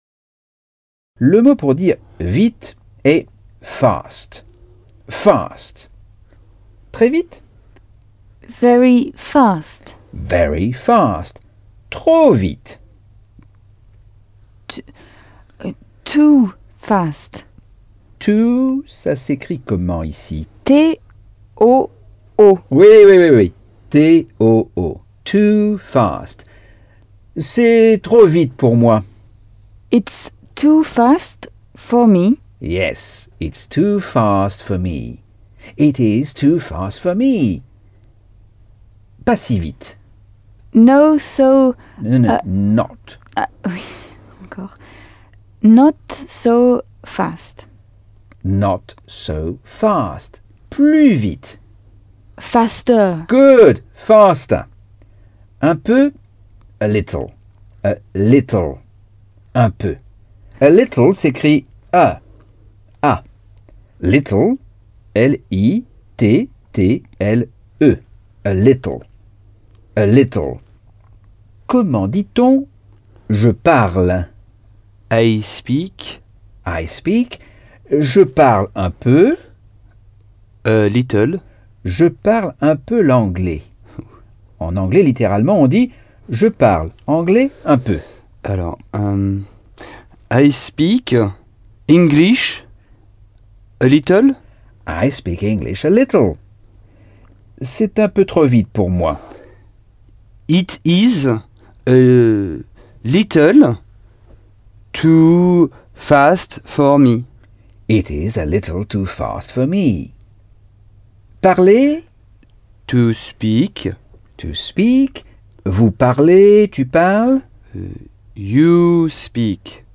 Leçon 1 - Cours audio Anglais par Michel Thomas